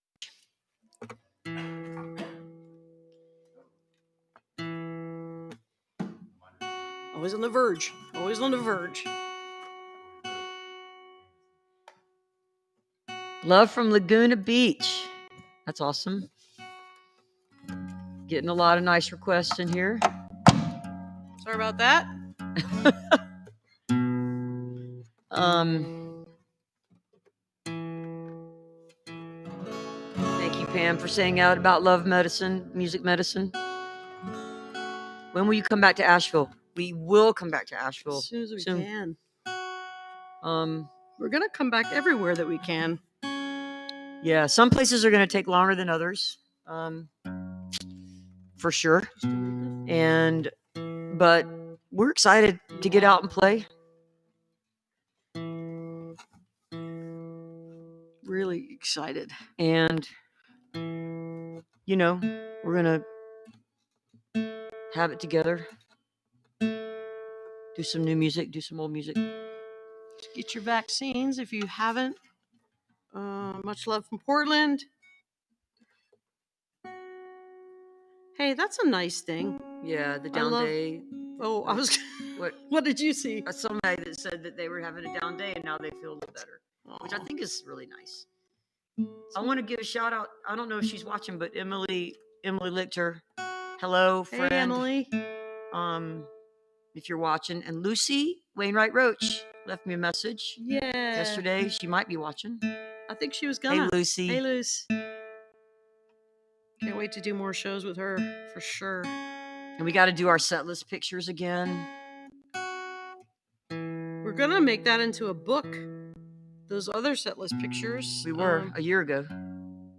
(captured from the youtube livestream)
01. talking with the crowd (2:53)